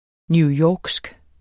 Udtale [ njuˈjɒːgsg ]